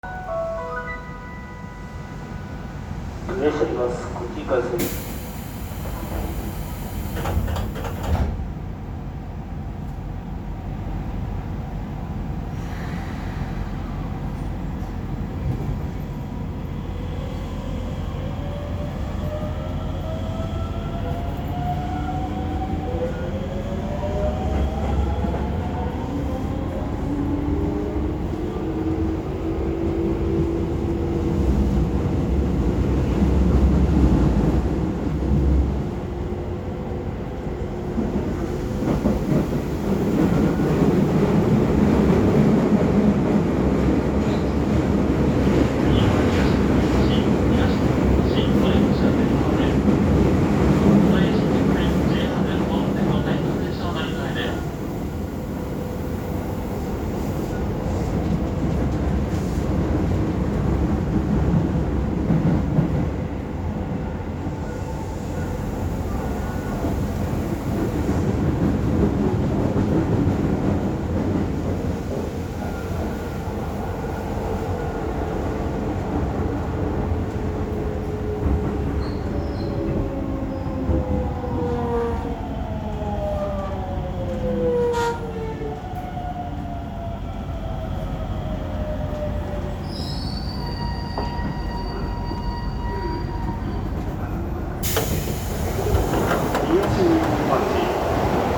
・7300形走行音
【都営浅草線】人形町→東日本橋
ごく一般的な東洋GTOで、京成3700形、北総9800形、7800形と音での判別は不可能です（かつてはドアエンジンの音が若干違っていたものの誤差の範囲）。都営浅草線の自動放送には対応していません。